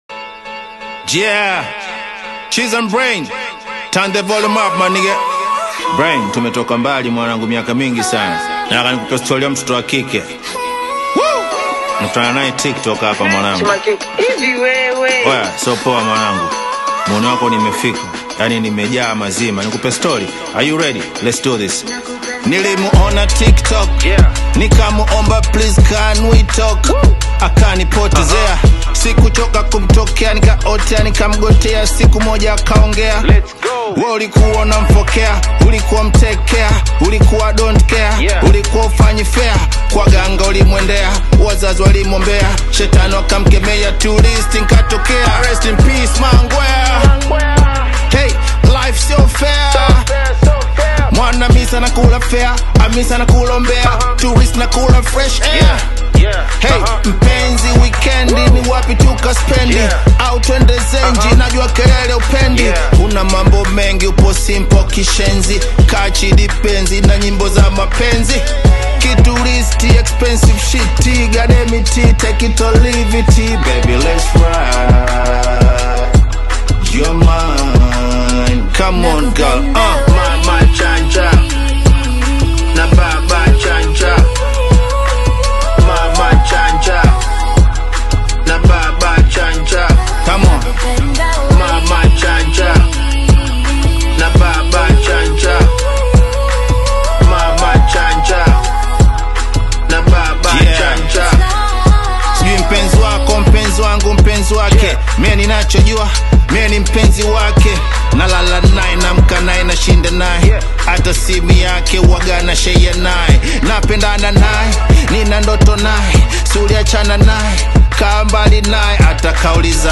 culturally rich Hip Hop single